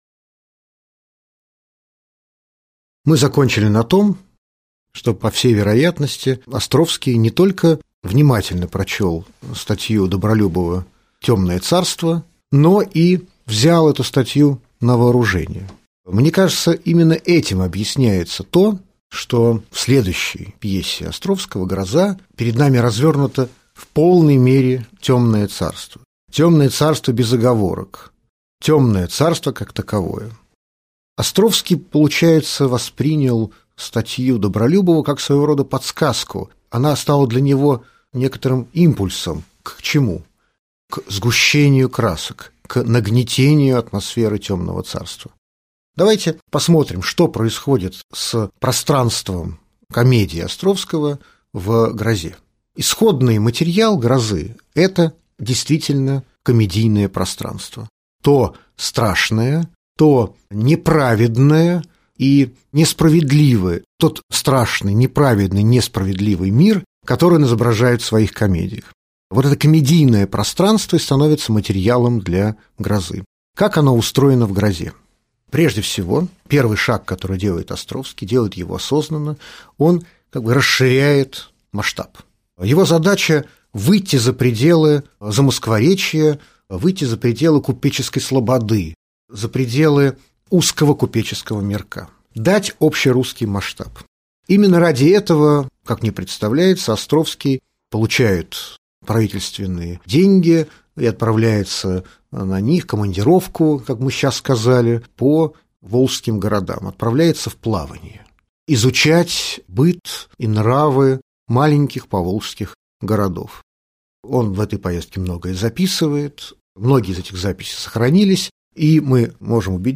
Аудиокнига Лекция «Преодоление комедии в «Грозе» А. Островского» | Библиотека аудиокниг